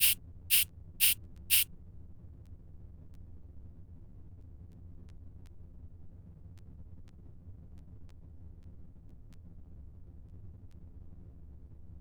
MenuSwish.wav